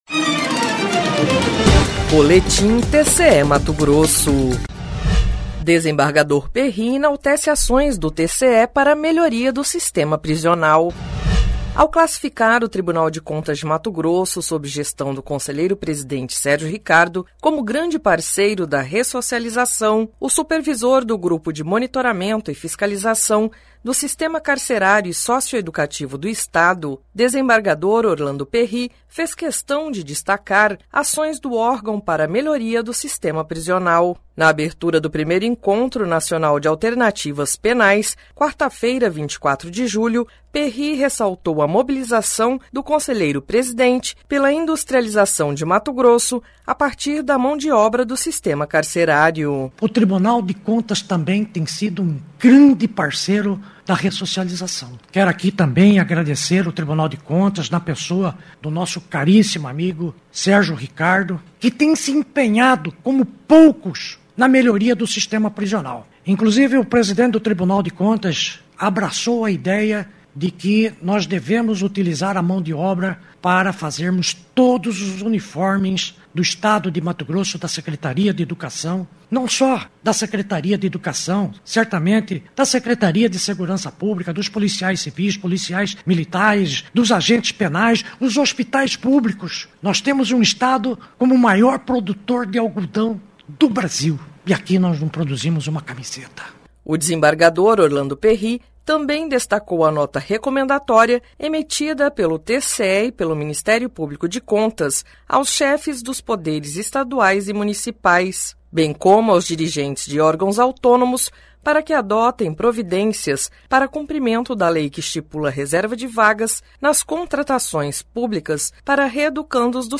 Sonora: Orlando Perri - desembargador supervisor do Grupo de Monitoramento e Fiscalização do Sistema Carcerário e Socioeducativo de MT